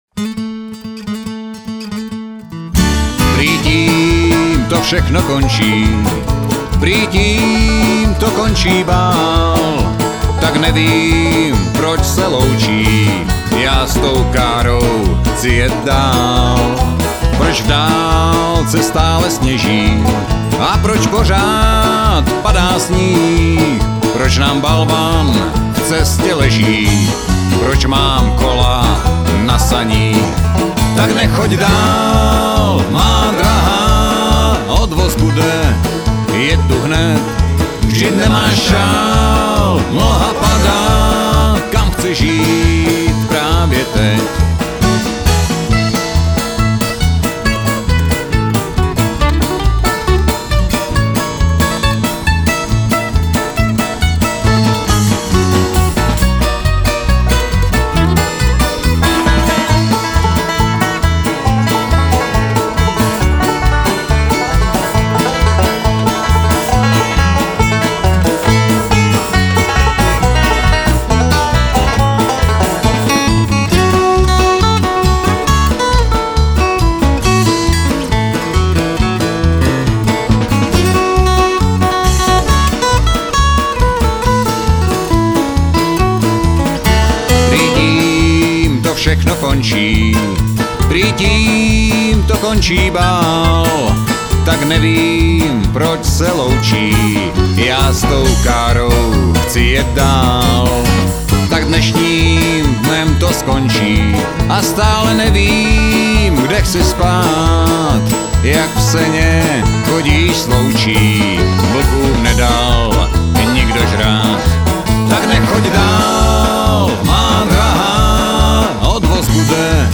"salonní country"
bohatý repertoár (country, folk, bluegrass ...)